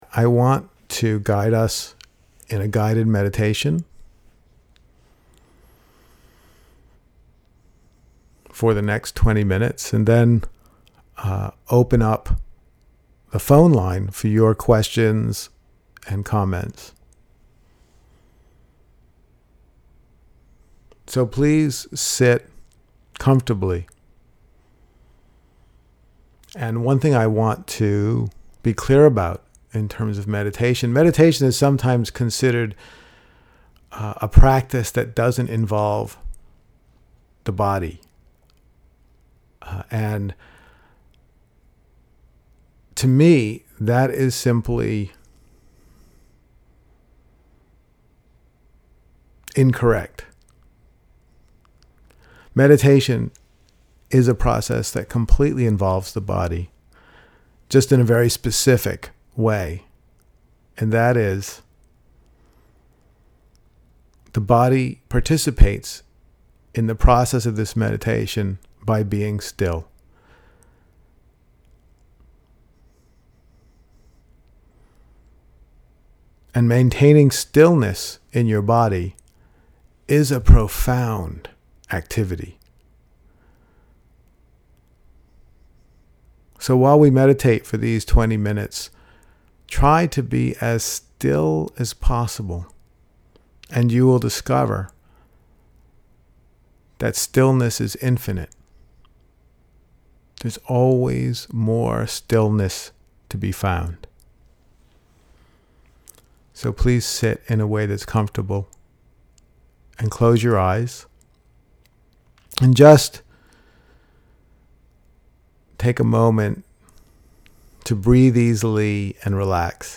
The-Transformative-Power-of-Contentment-Guided-Meditation.mp3